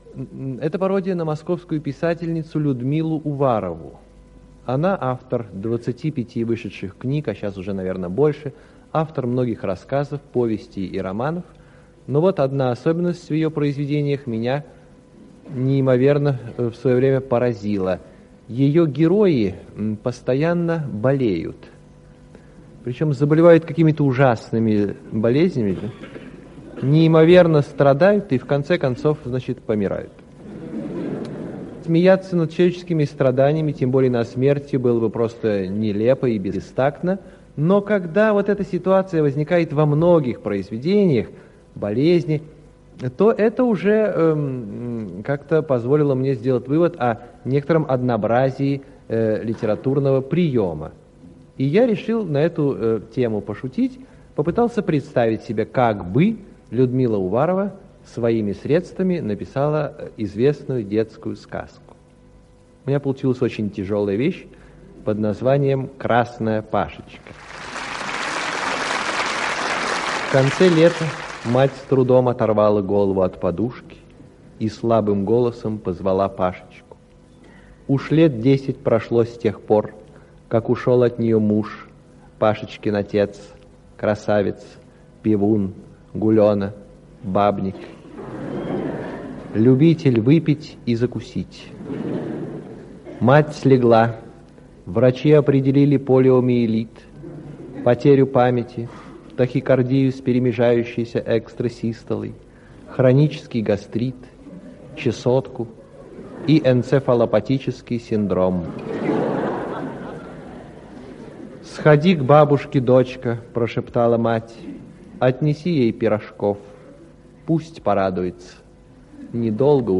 Александр Иванов читает свою пародию под названием «Красная Пашечка»